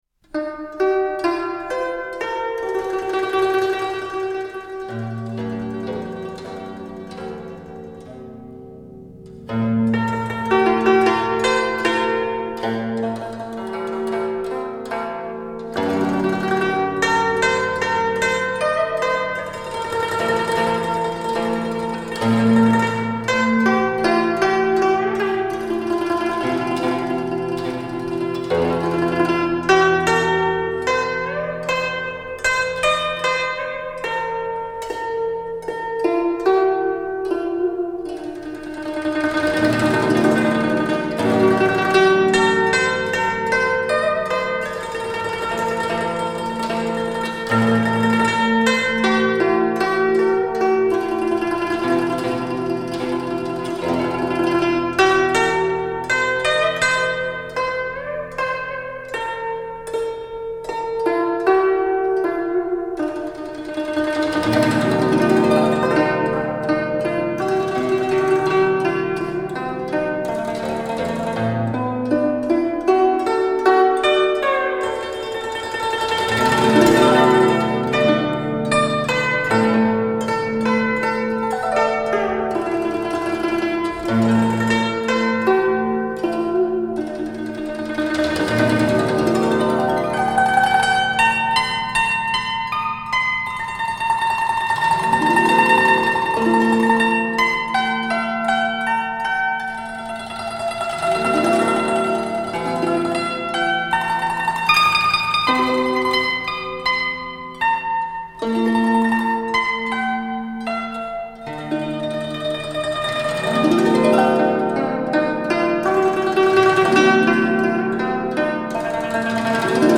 古筝演奏
流行古典风尚重现  民族乐韵色彩闪烁
古筝音调清丽温婉，轻快通透。
以古筝全新演绎流行音乐和民族音乐，使东方传统器乐脱胎换骨，给你意想不到的惊喜。